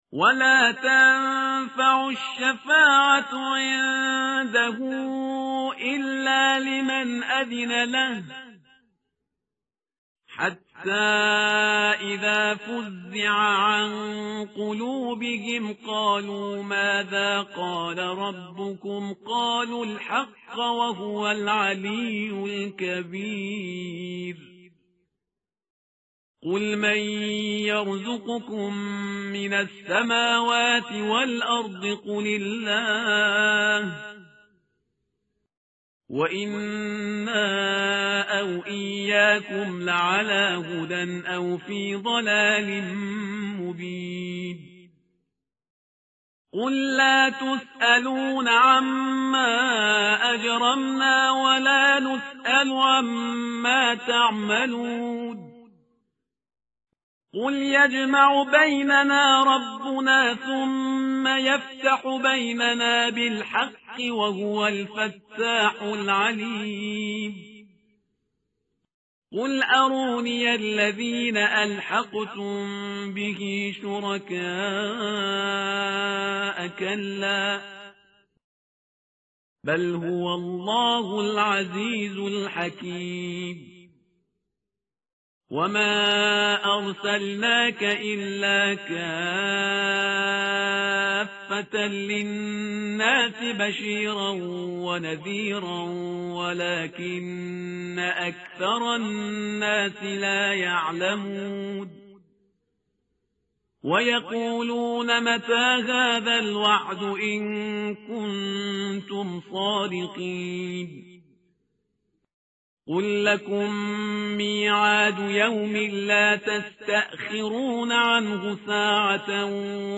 tartil_parhizgar_page_431.mp3